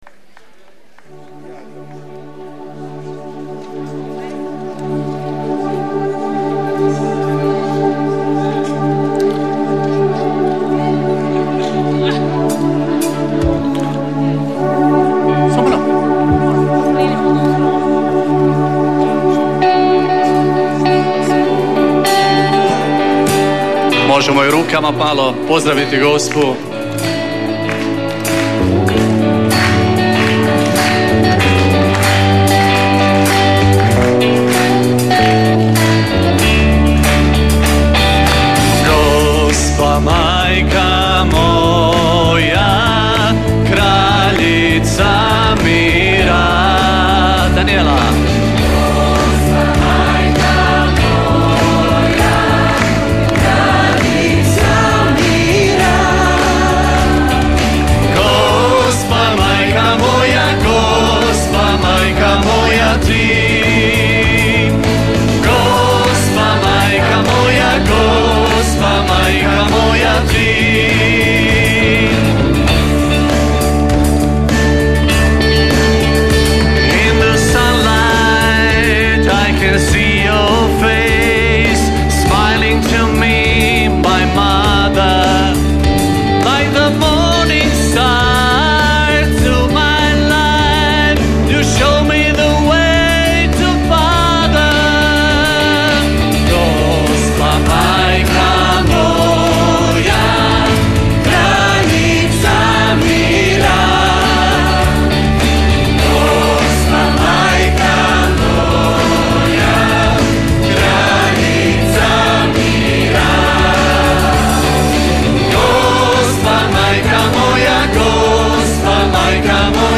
rock  ..22024 ..